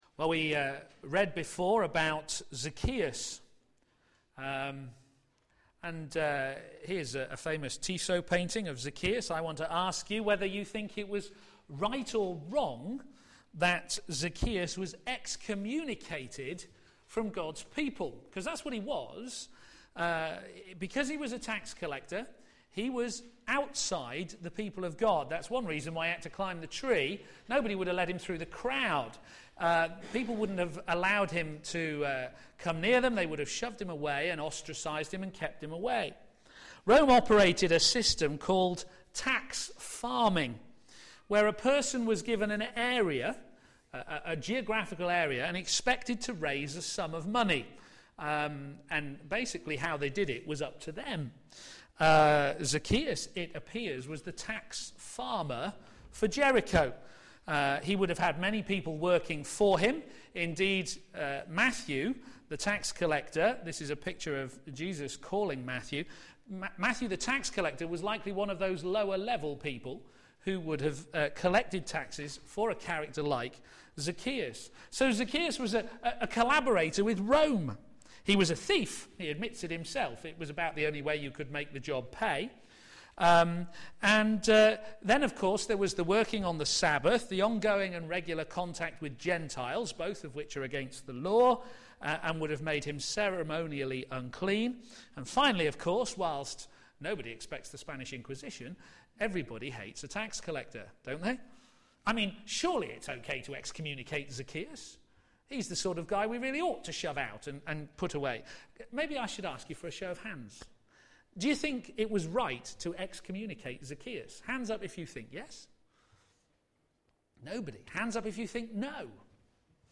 a.m. Service on Sun 20th May 2012 10:30
Theme: The Church - It's Message, Defending the Faith Sermon